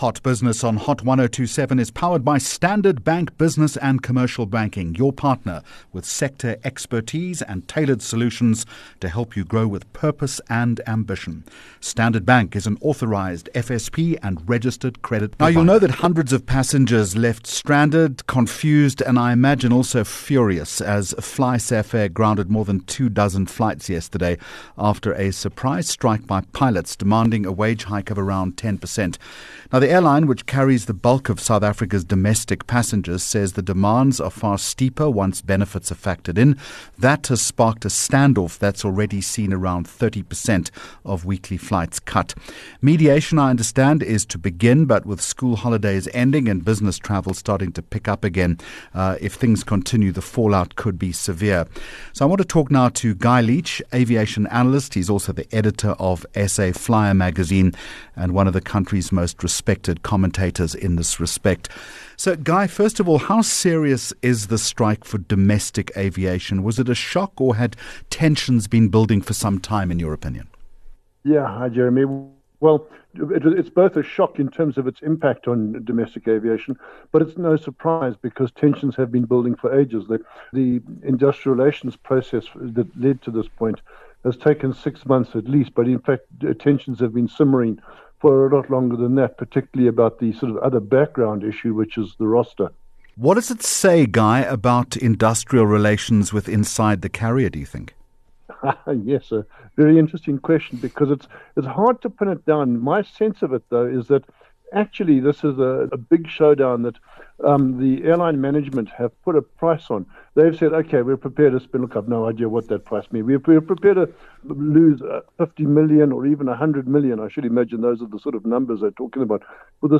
22 Jul Hot Business Interview